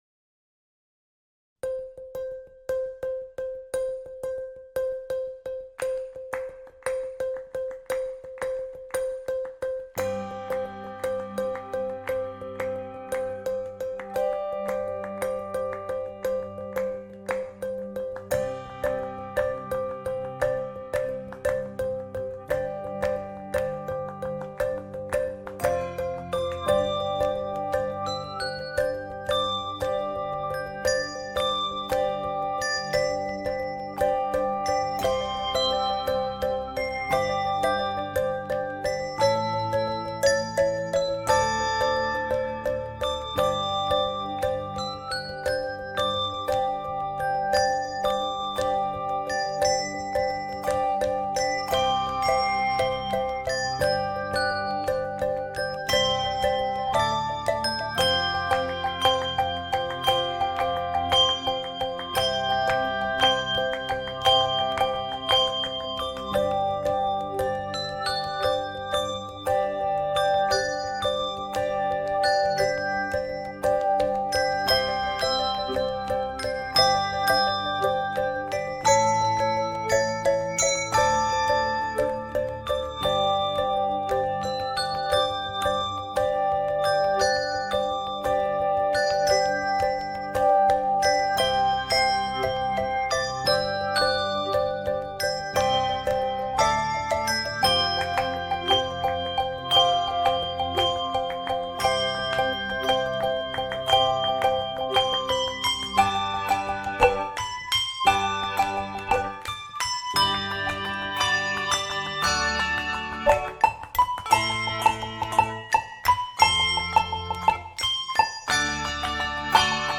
This rhythmic, expressive, and elegant setting